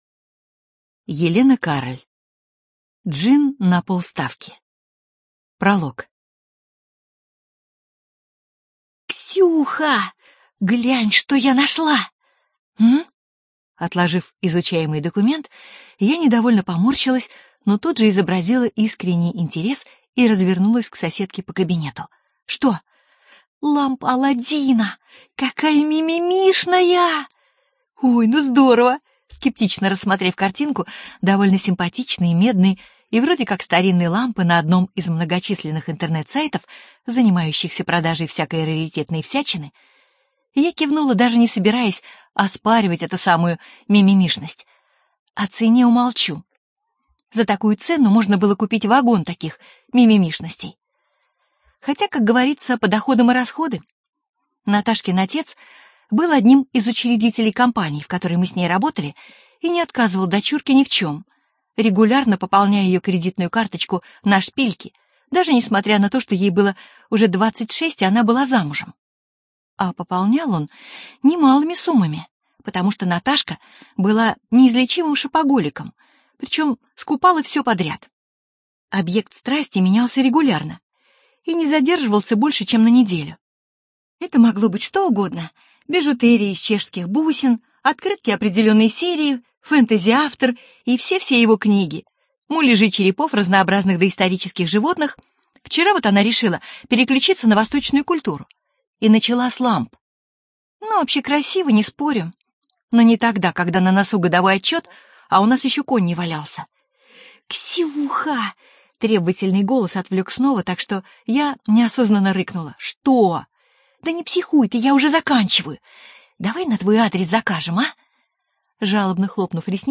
Студия звукозаписиАрдис